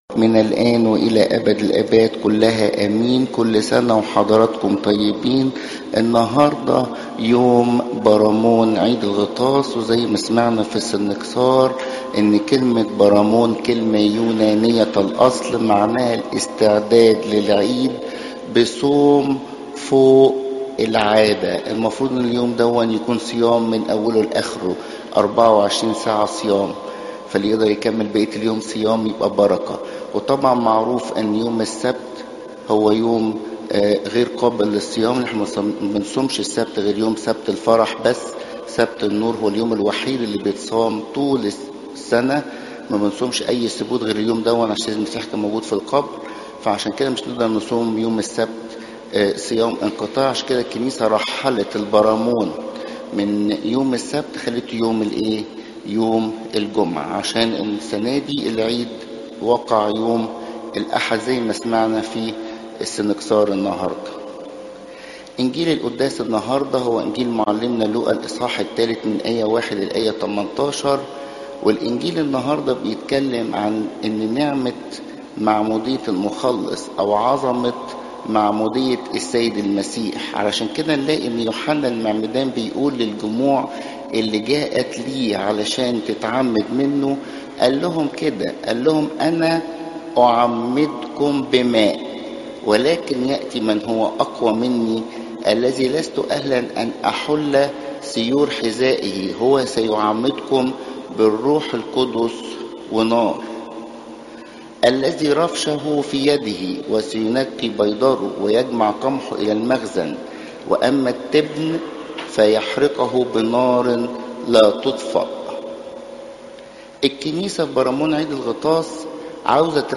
17 January 2025 21:43 Sermons events Hits